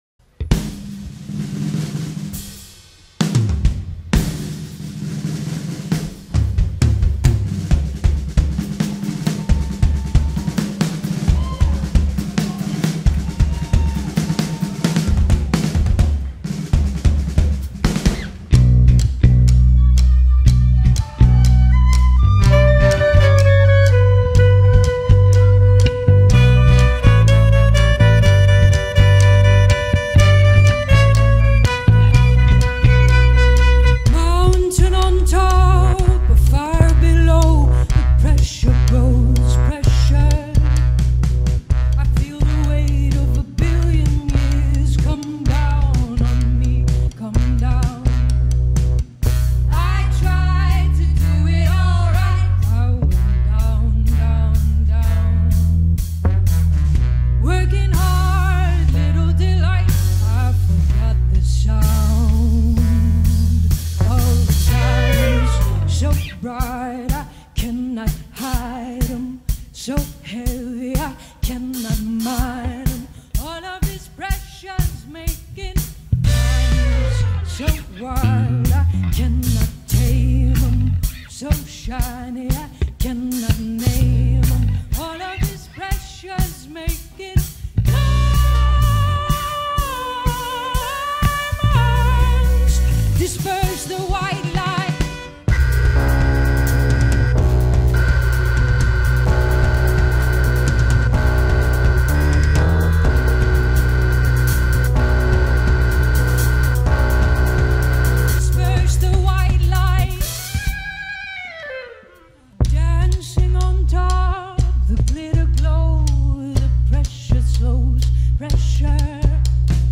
Recorded August 25, 2015 - Haldern Pop Festival.